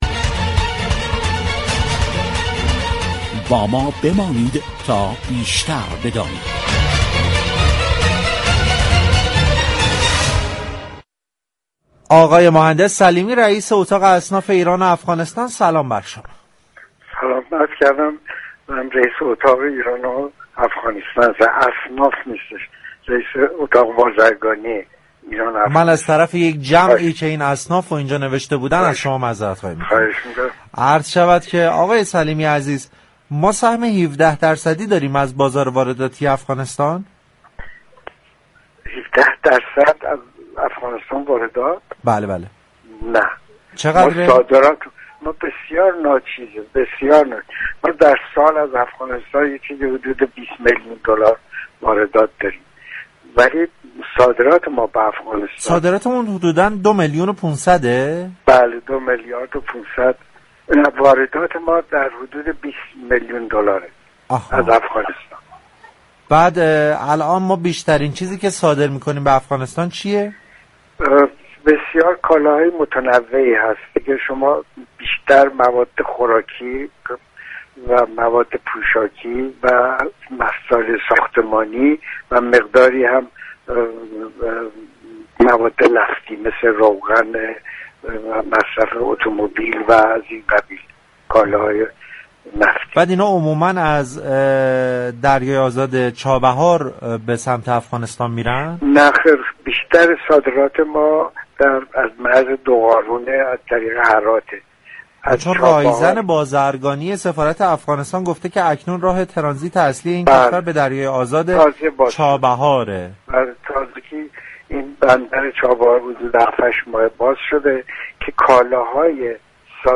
در گفتگوی تلفنی با برنامه بازار تهران رادیو تهران